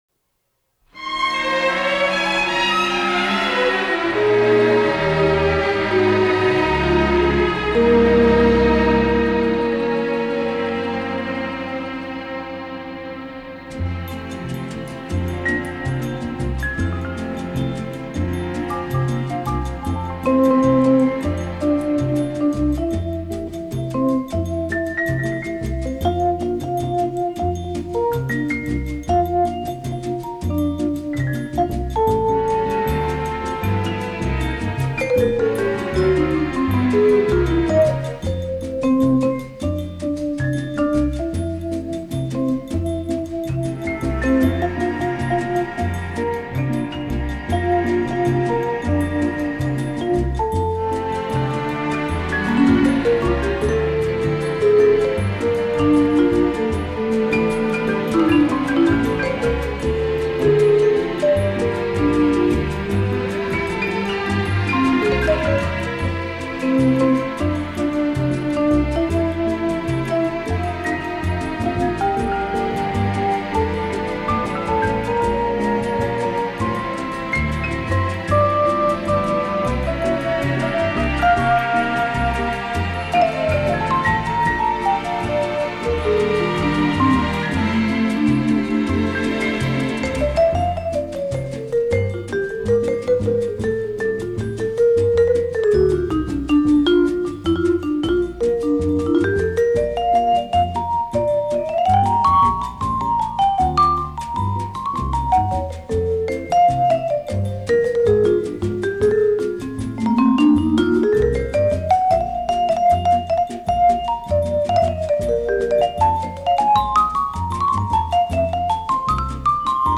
lush Latin jazz